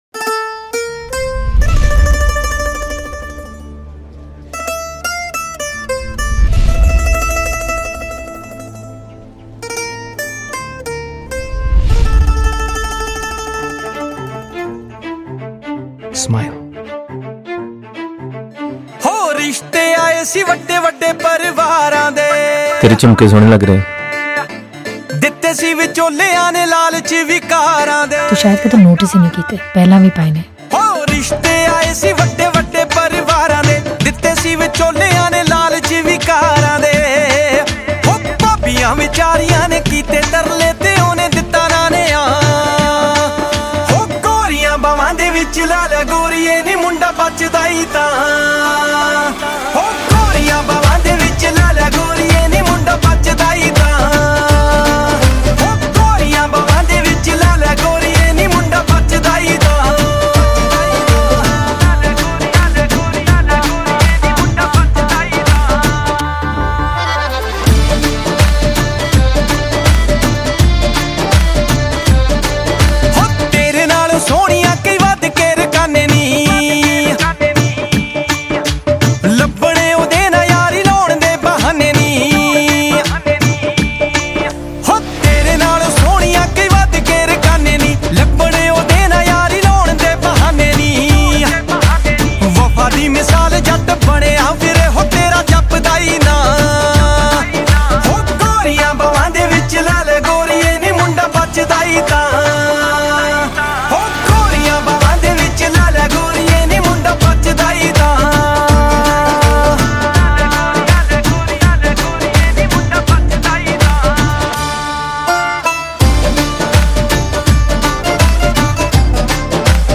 Mp3 Files / Bhangra /